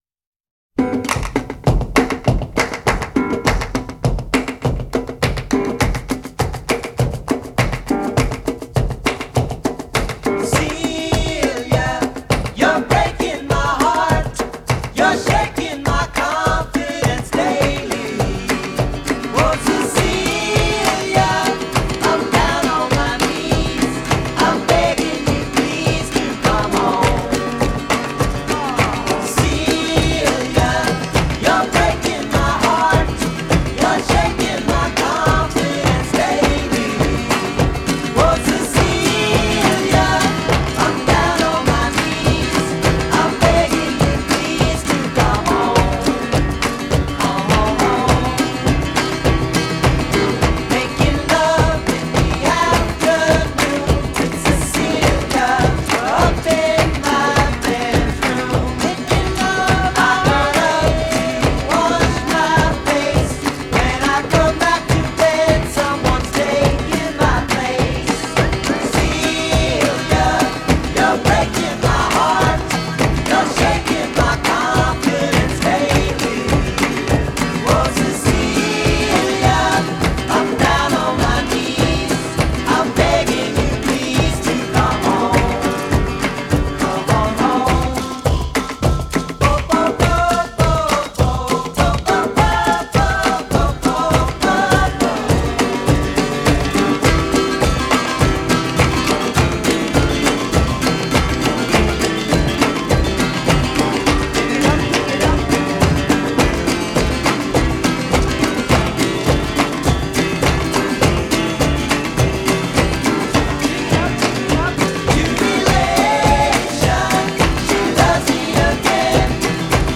Жанр: Folk Rock, Folk, Pop